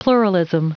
Prononciation du mot pluralism en anglais (fichier audio)
Prononciation du mot : pluralism